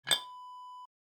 Ceramic Jar Lid Open Wav Sound Effect #2
Description: Opening sound of a ceramic jar lid
Properties: 48.000 kHz 24-bit Stereo
A beep sound is embedded in the audio preview file but it is not present in the high resolution downloadable wav file.
Keywords: open, opening, lid, lift, lifting, up
ceramic-jar-open-preview-2.mp3